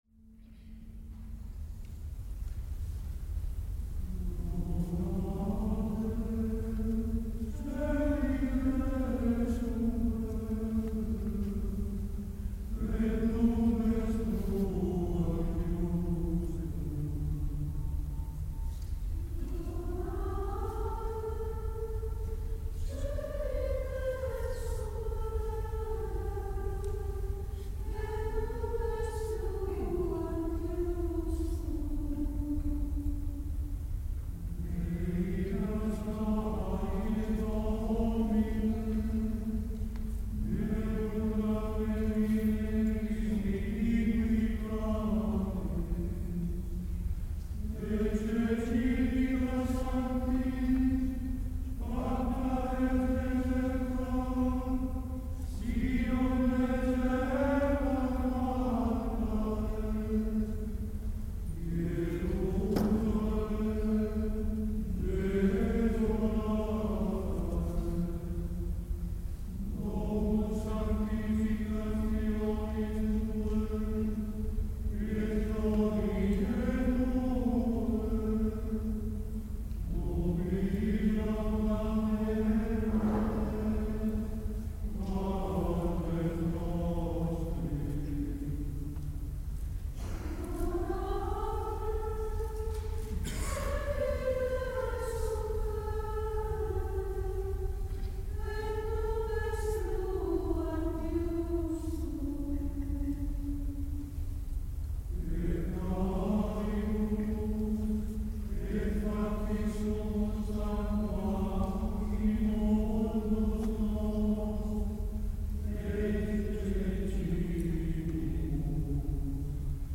S. Gaudenzio church choir Gambolo' (PV) Italy
Lunedi 17 dicembre ore 21.00  Chiesa Parrocchiale   Gambolò    Concerto di Natale
Rorate coeli -      (gregoriano)